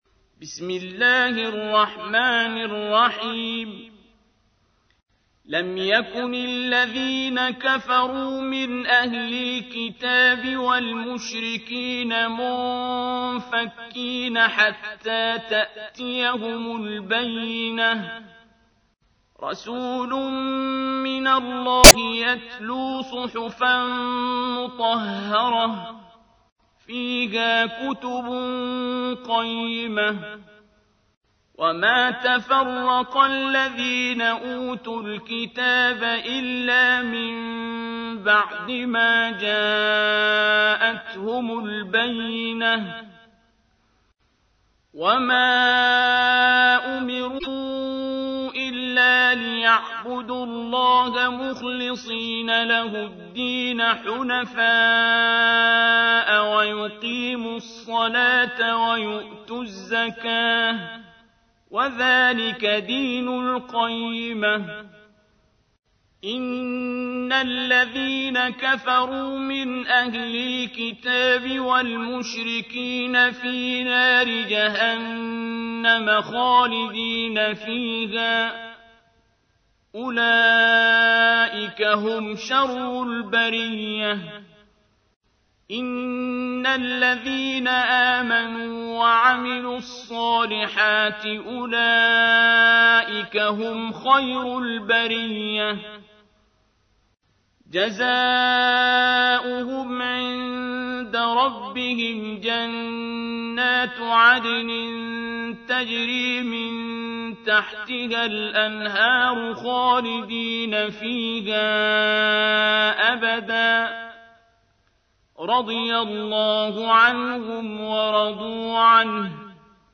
تحميل : 98. سورة البينة / القارئ عبد الباسط عبد الصمد / القرآن الكريم / موقع يا حسين